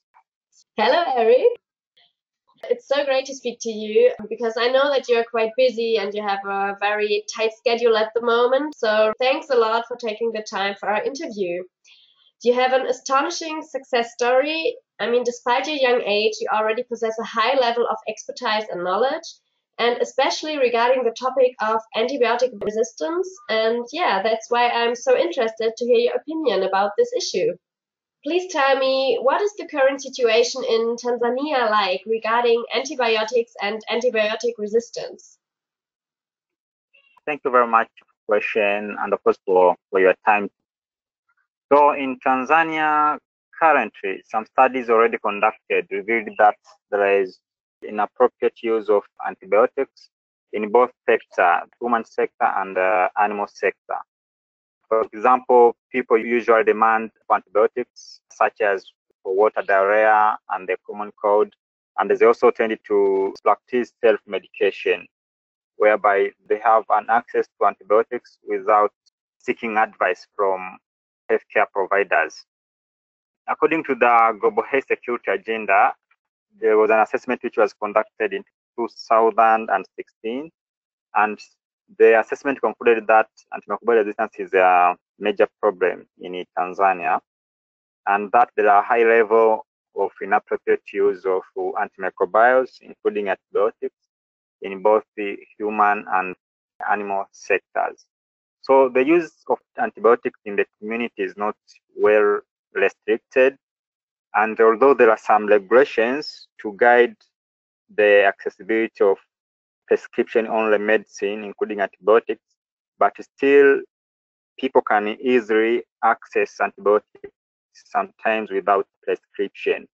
Interview mit einem jugen Apotheker aus Tansania